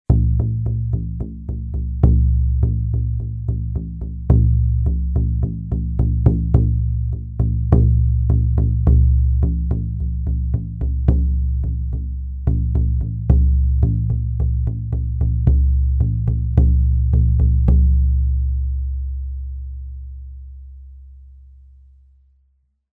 Schamanentrommel mit Fell
Diese leichten Rahmentrommeln sind mit Ziegenfell bespannt. Die Haare des Fells machen den Sound etwas weicher & leiser.
Die Trommeln haben einen vollen Klang und werden mit Holzschlegel geliefert.
Schamanentrommel-Ziege-Fell-leichte-Rahmentrommel-mit-voll.mp3